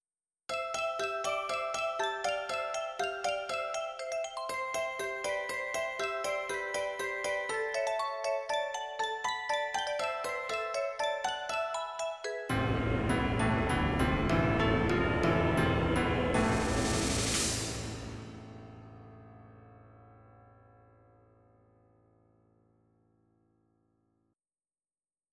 Dark arrange version of famous works